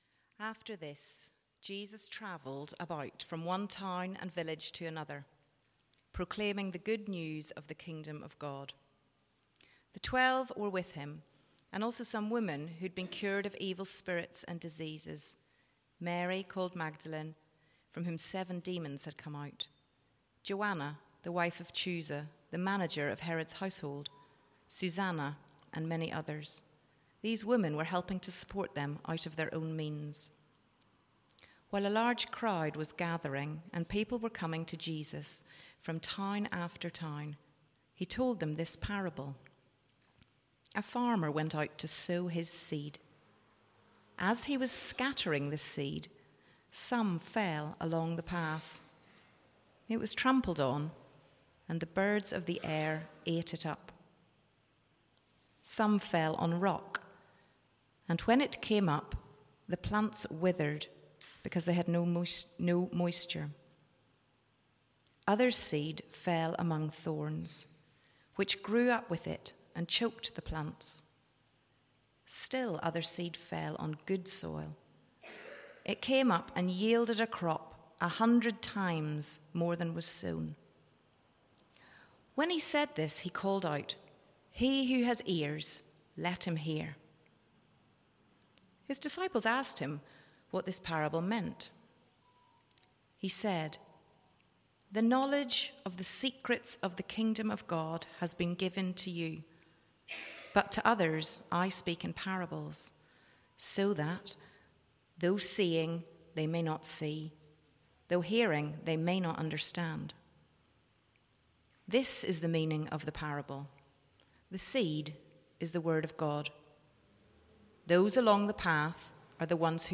Sunday Service
Theme: Parable of the Sower Sermon